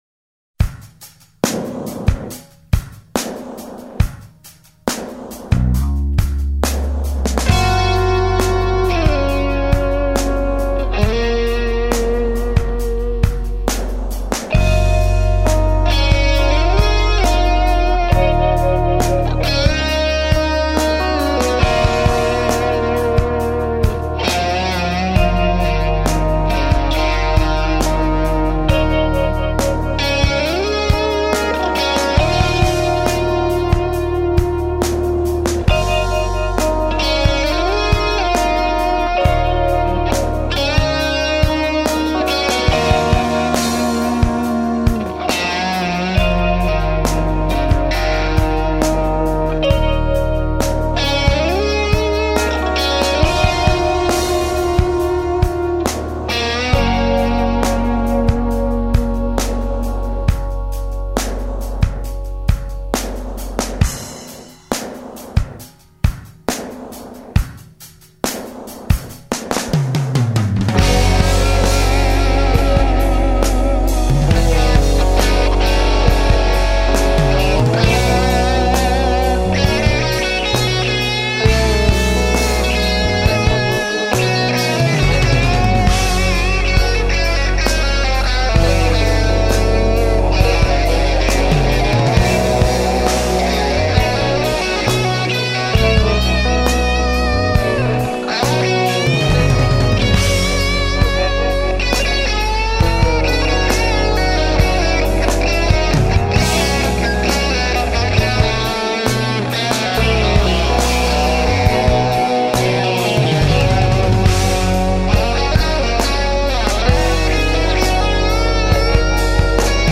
Experimental improvisation band.
all guitars, lap steel